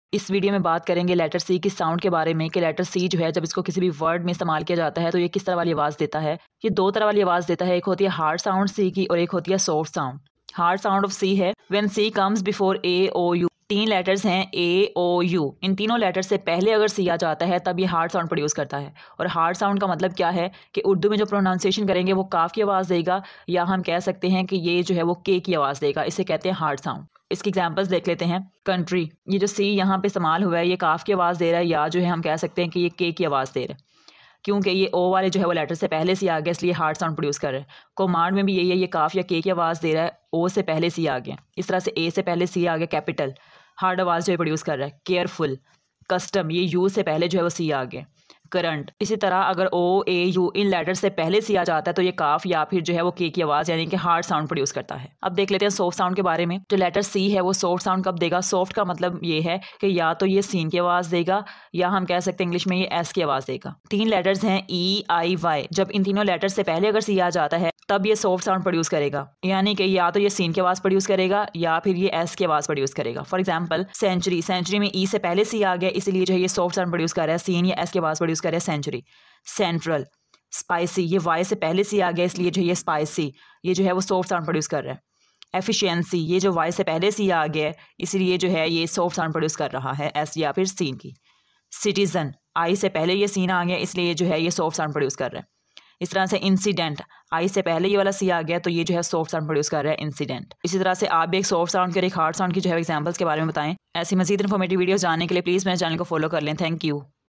Hard sound of letter c. soft sound of letter c. Use of C in words, English words pronounciation. pronounciation of words with c letter.